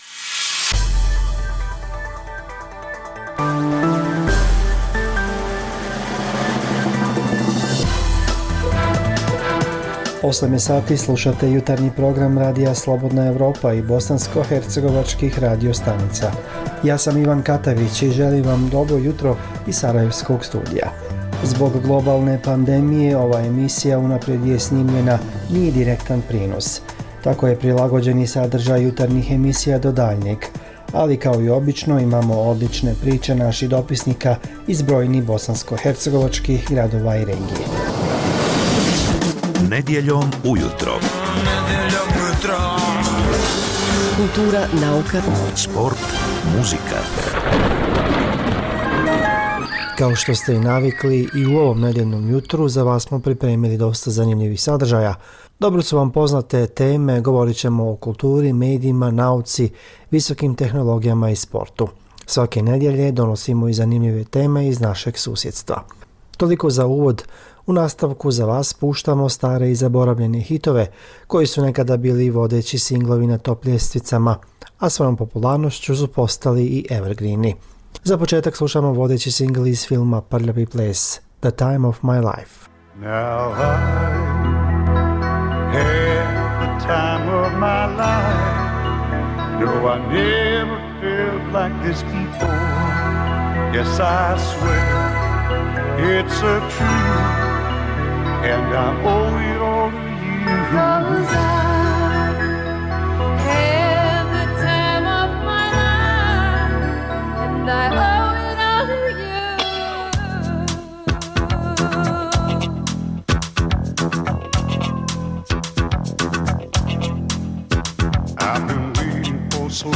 Nedjeljni jutarnji program za Bosnu i Hercegovinu. Zbog pooštrenih mjera kretanja u cilju sprječavanja zaraze korona virusom, ovaj program je unaprijed snimljen.